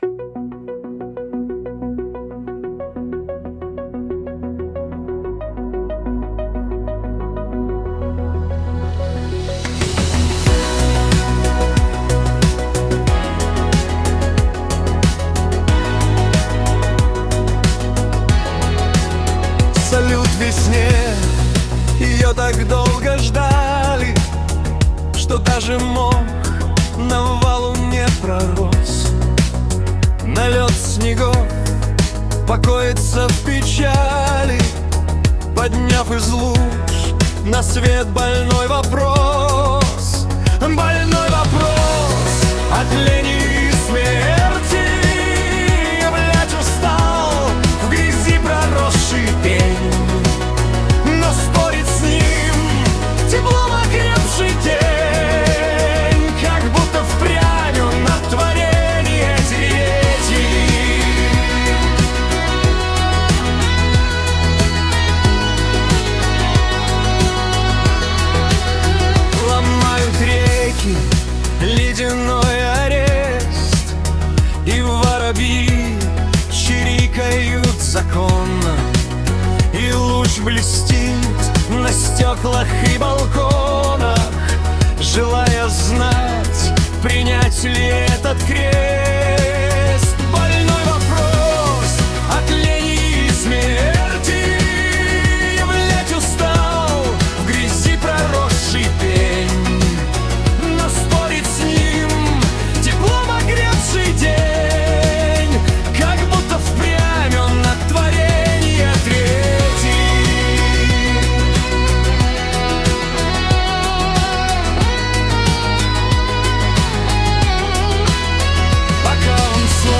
Чиллвэйв 3в 2дб